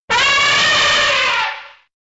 AA_sound_elephant.ogg